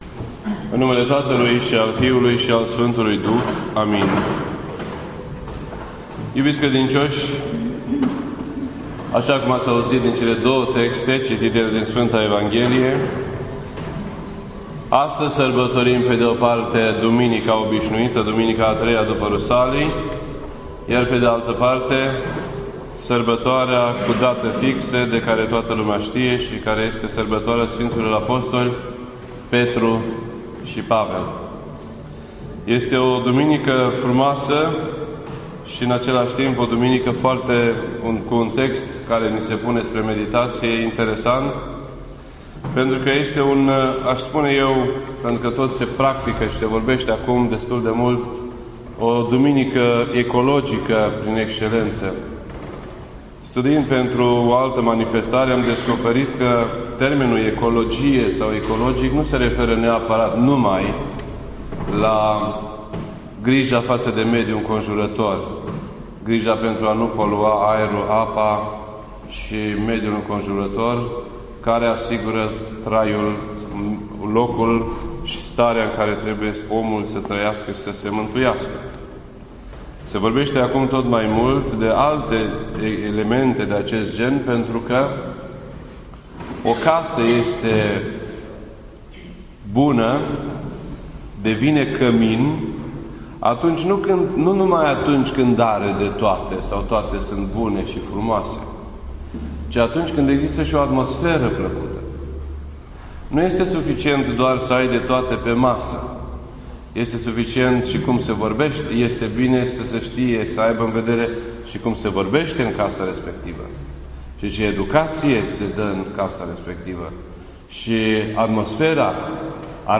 This entry was posted on Sunday, June 29th, 2014 at 11:56 AM and is filed under Predici ortodoxe in format audio.